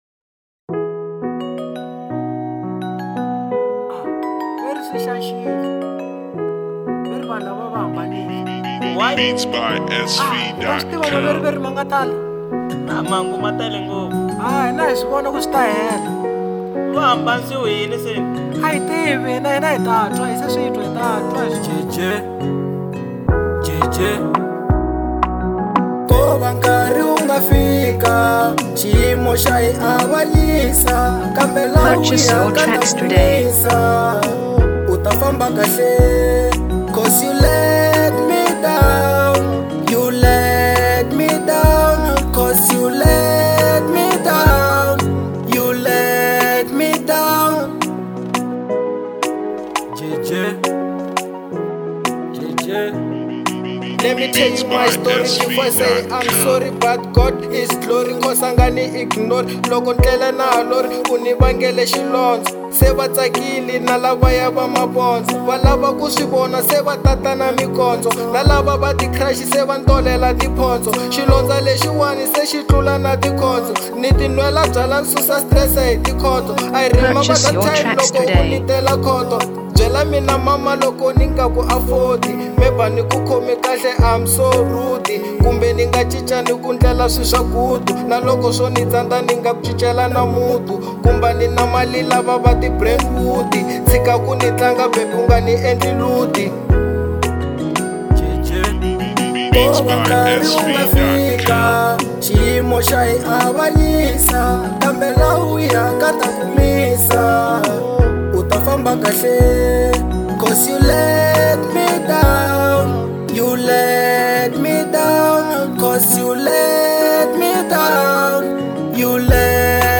04:21 Genre : Afro Pop Size